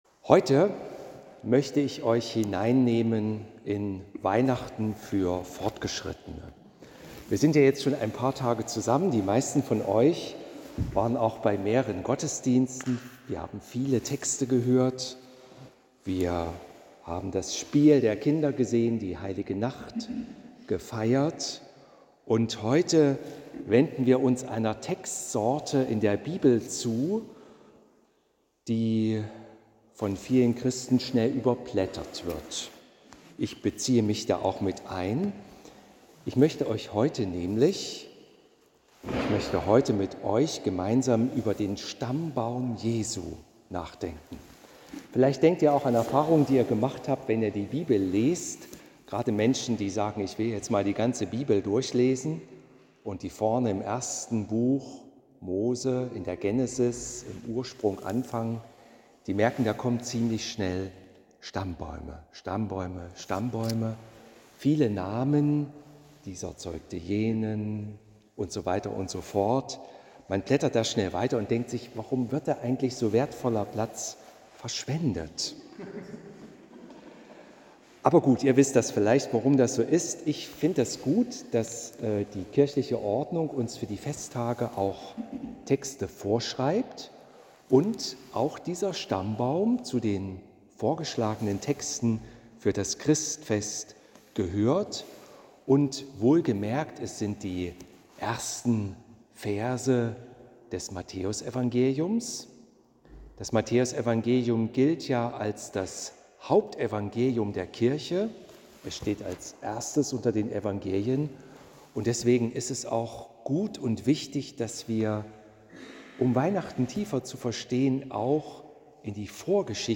Hören Sie hier die Predigt zu Matthäus 1,1-17